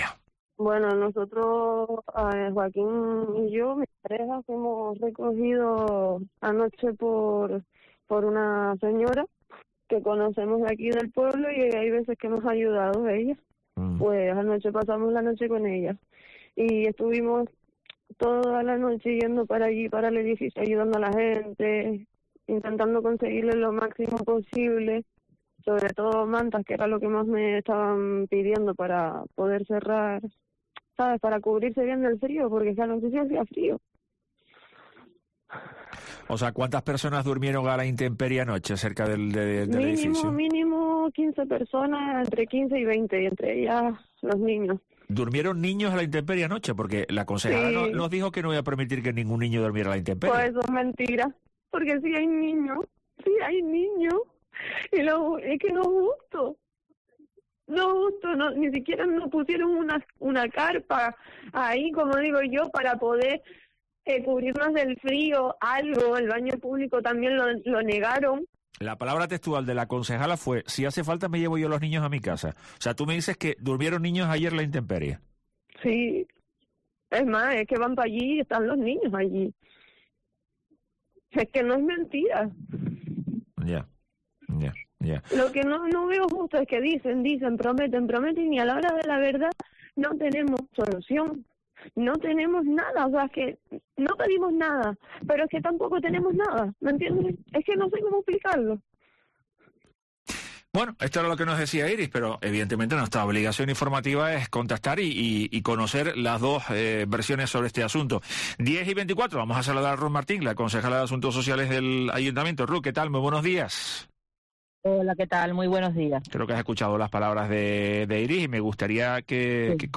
En tono enfadado, insistió en que “a todos los niños que estaban allí se les buscó una alternativa habitacional”, y, “si han dormido esta noche a la intemperie, es una irresponsabilidad por parte de sus padres, porque tenían un techo en el que dormir”.